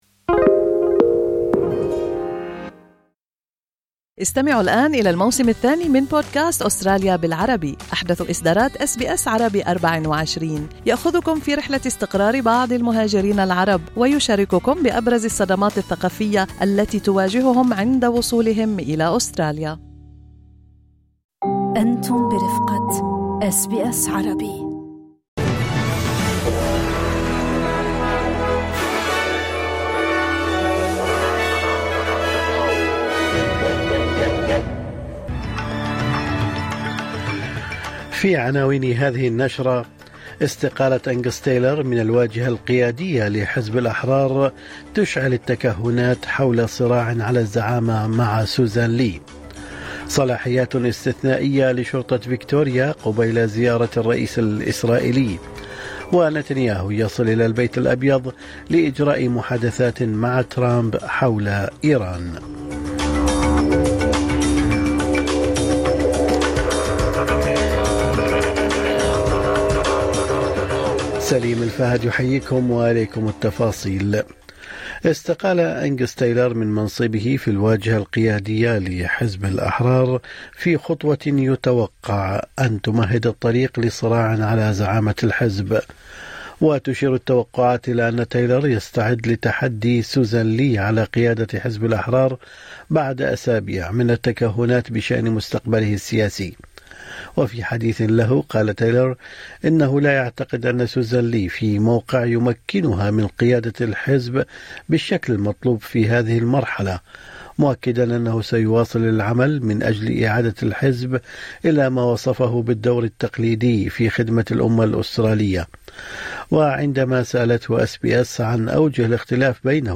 نشرة اخبار الصباح 12/2/2026